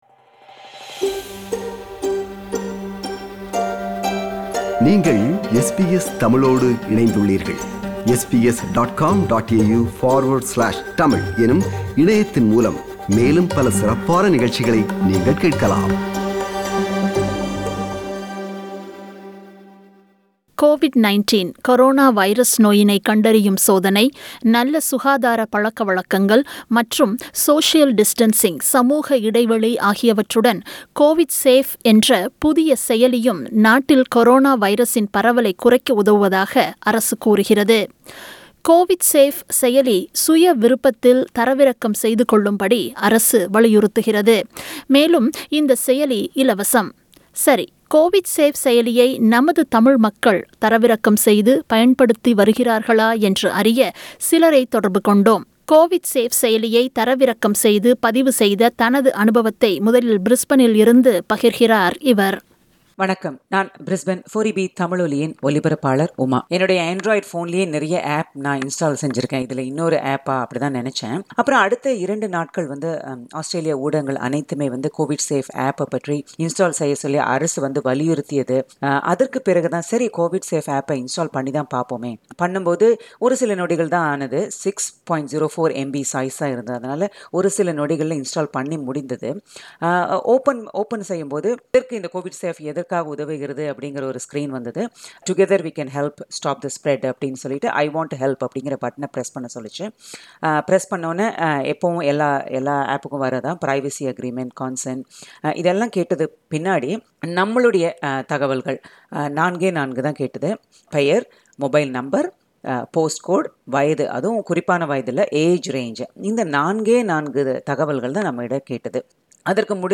Voxpop on using COVIDSafe App